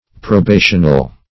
Probational \Pro*ba"tion*al\, a.
probational.mp3